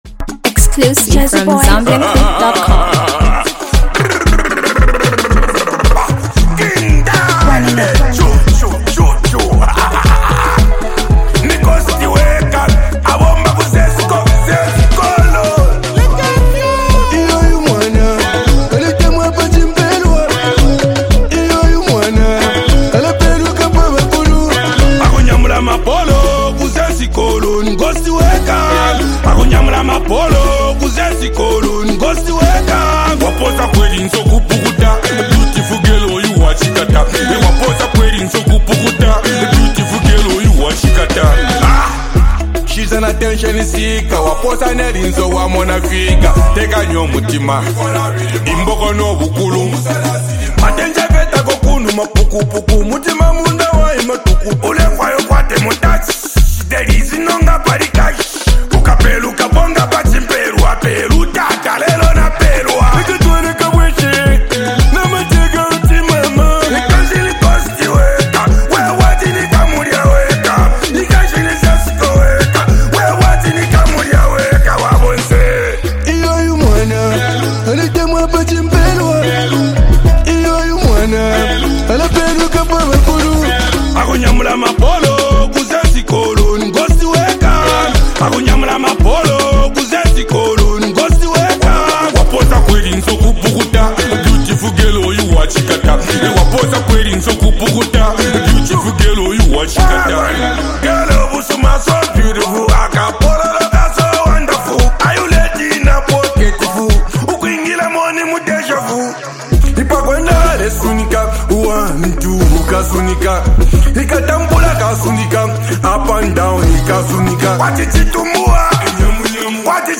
it’s a massive track you can dance to.